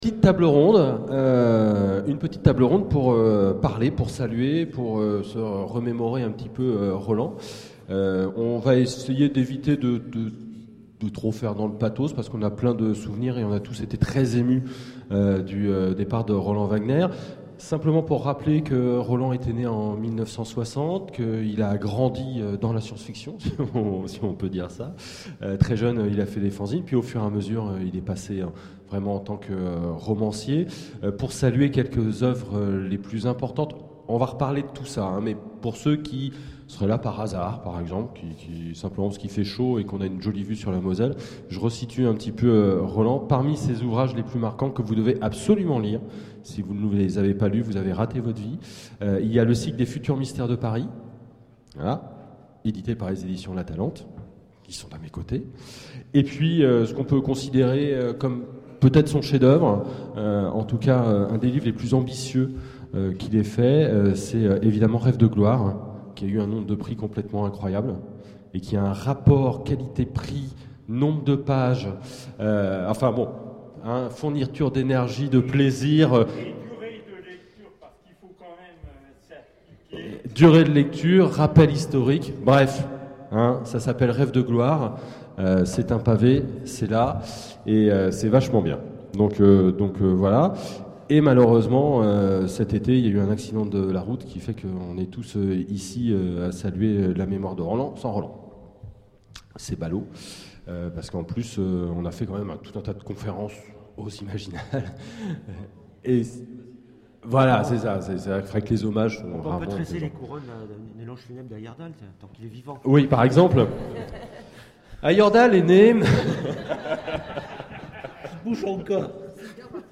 Imaginales 2013 : Conférence Pour saluer Roland C. Wagner !